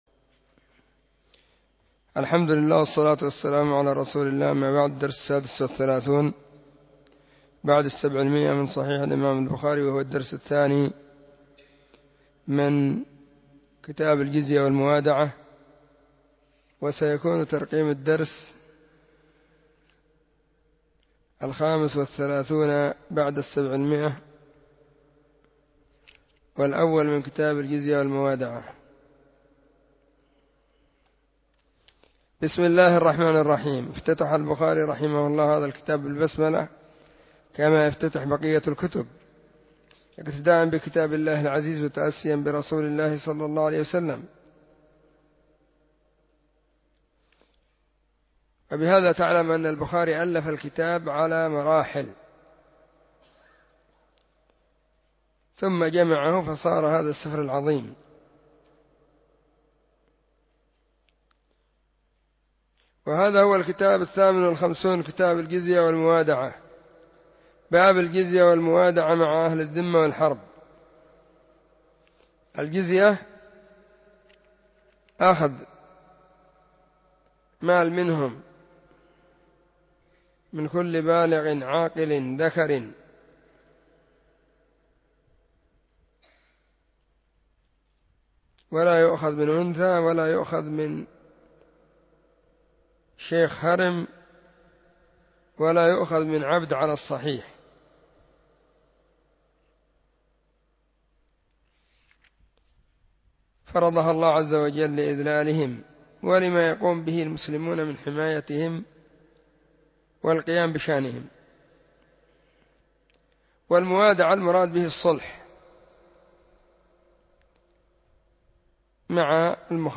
🕐 [بين مغرب وعشاء – الدرس الثاني]
🕐 [بين مغرب وعشاء – الدرس الثاني] 📢 مسجد الصحابة – بالغيضة – المهرة، اليمن حرسها الله.
كتاب-الجزية-والموادعة-الدرس-1.mp3